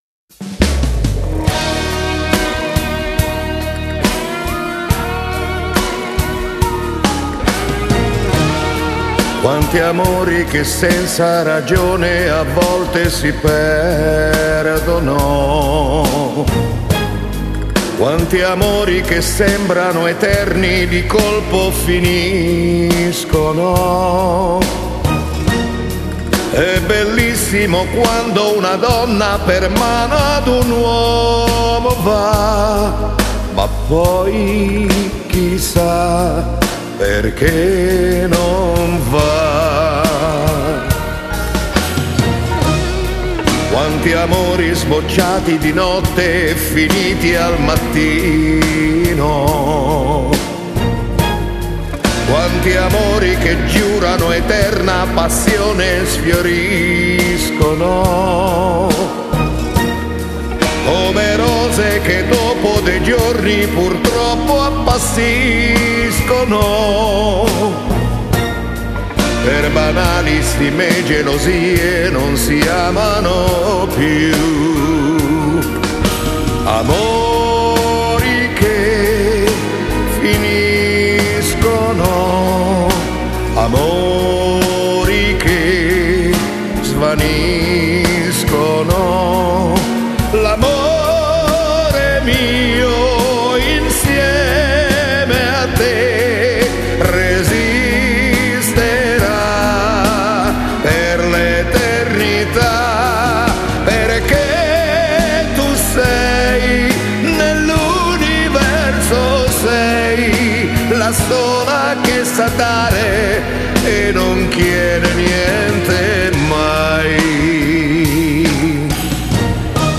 Genere: Lento